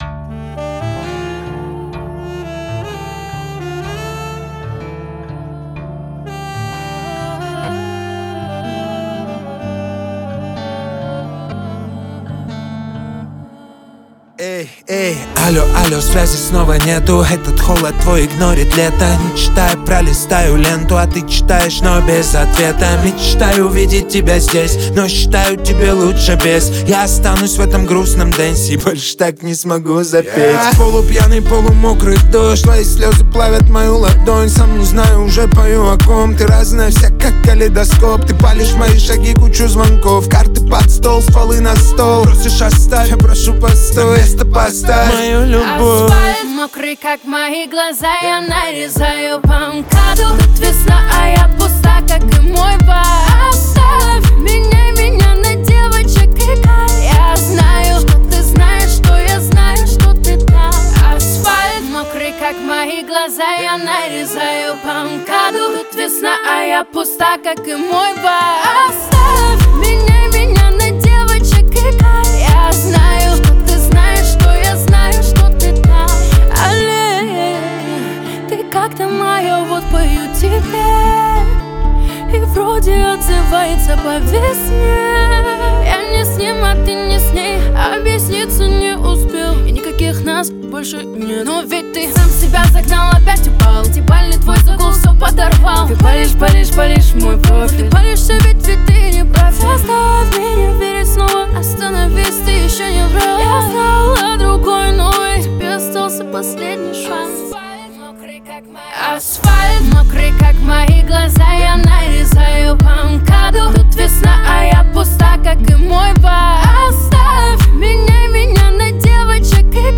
Клубные хиты